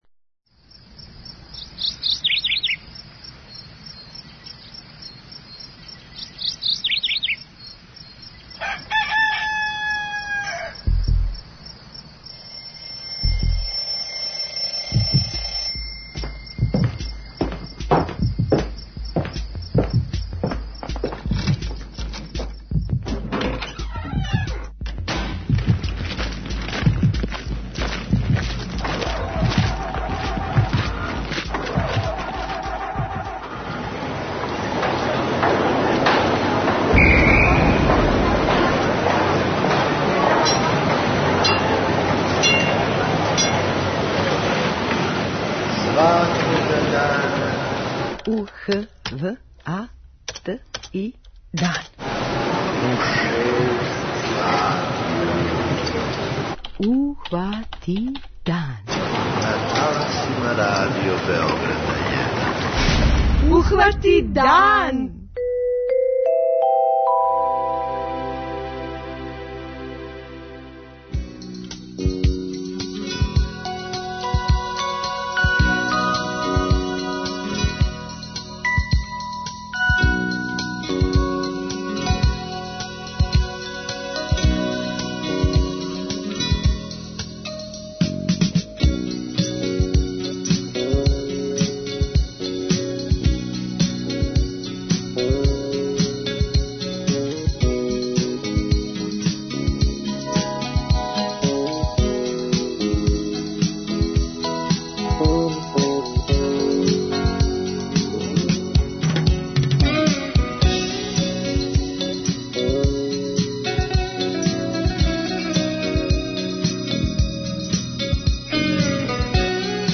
преузми : 28.66 MB Ухвати дан Autor: Група аутора Јутарњи програм Радио Београда 1!